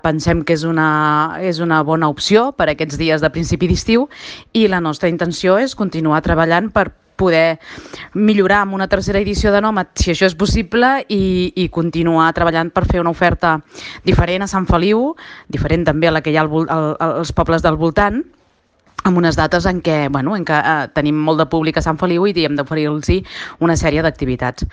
La regidora d’Activitat Econòmica, Sílvia Romero, en fa un balanç molt positiu i confirma que de cara al 2018 volen potenciar la proposta: